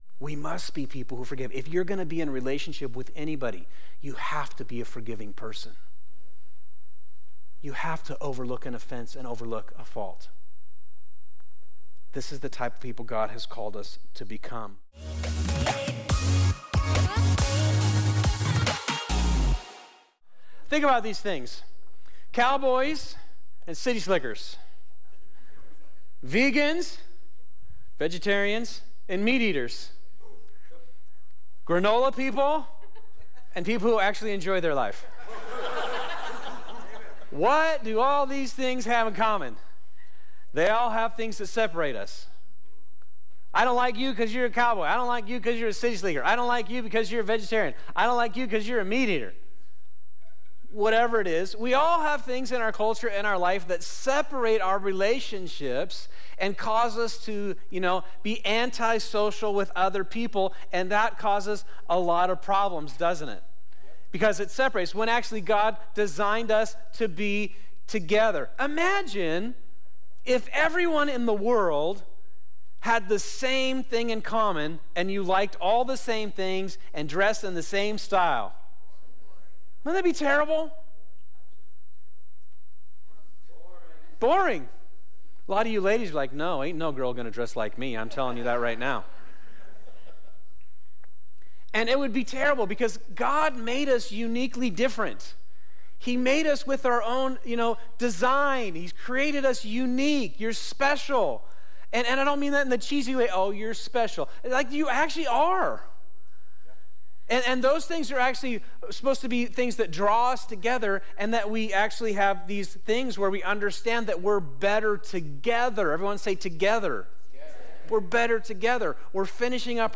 This is part 5 of our sermon series, "Relationships on Lock," at Fusion Christian Church. In this series, we discuss the importance of relationships and how to create and maintain healthy ones.